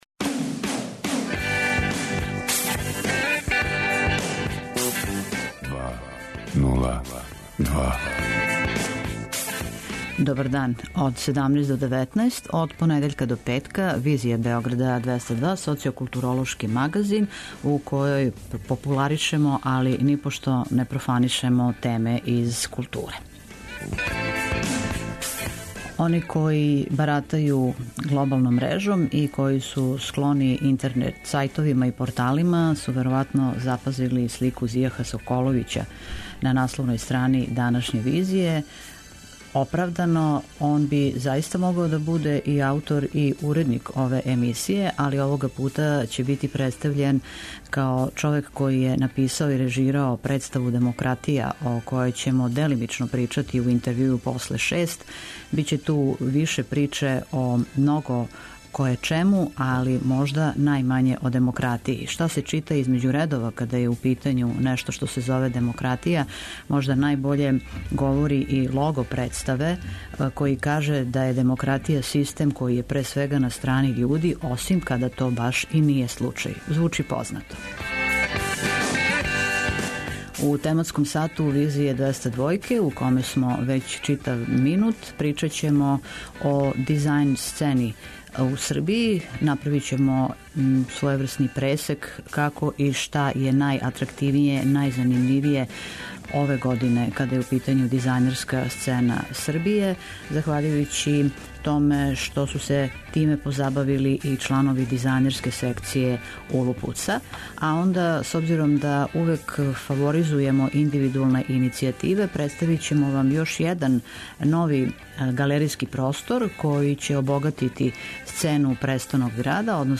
Гост: Зијах Соколовић.